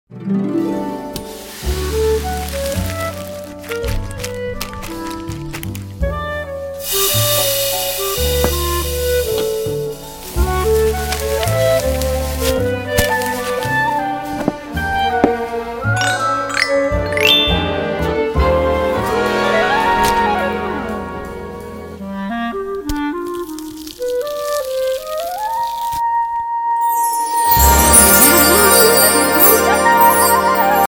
Anime Cooking Sounds :) Pure Sound Effects Free Download